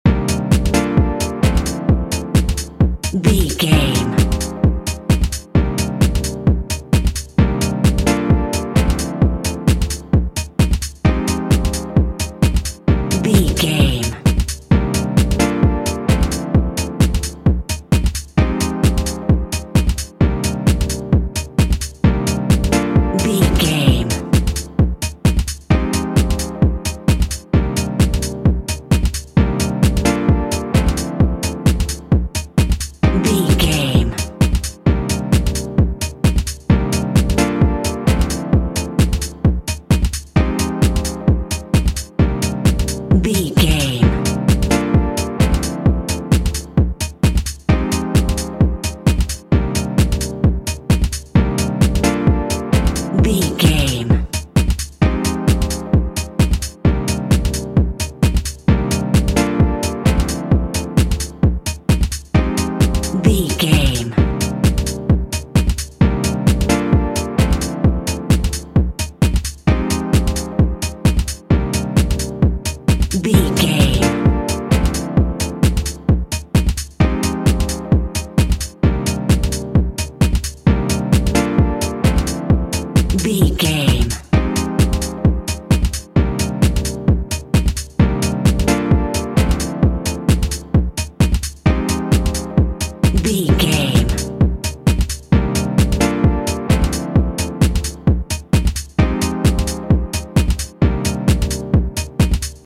1989 House Music.
Aeolian/Minor
funky
groovy
uplifting
driving
energetic
drums
synthesiser
drum machine
synth lead
synth bass